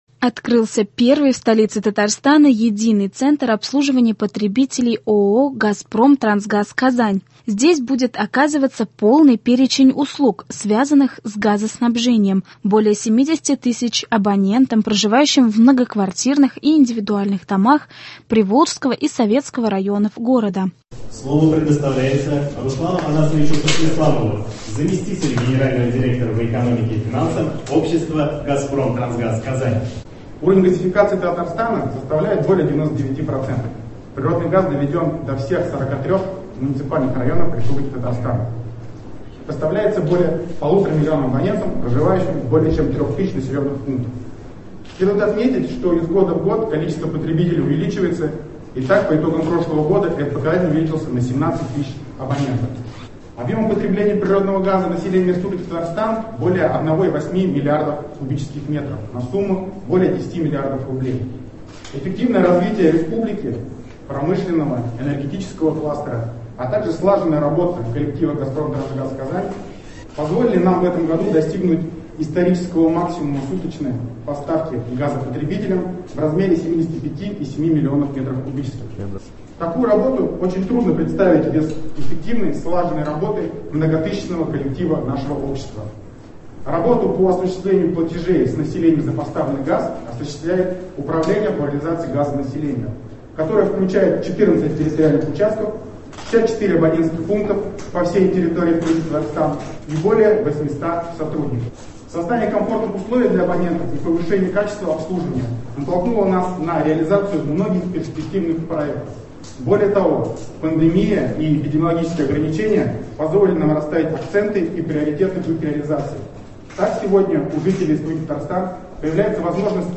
Актуальное интервью (23.03.21)